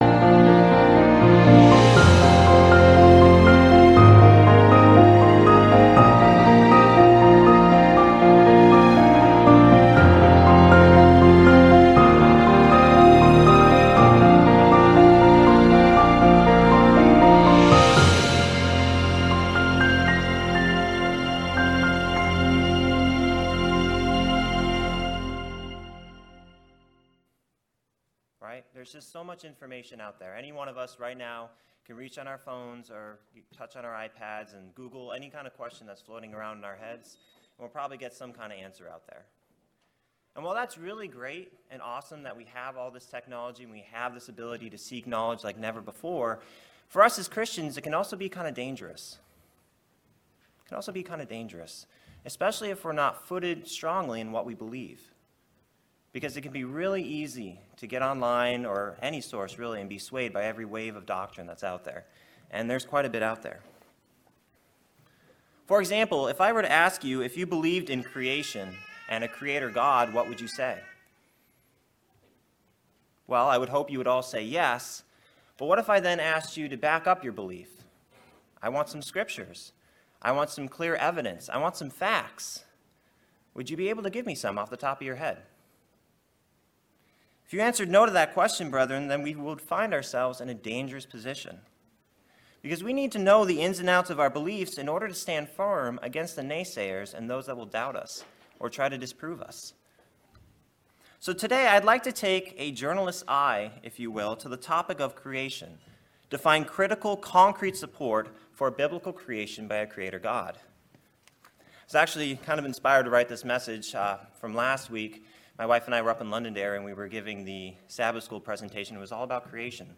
Children’s Bible lessons often cover the topic of creation, but have we taken a critical look at what it means to be a creator, and what we know about how creation was made? This split sermon looks at Who, What, when, how and why related to creation.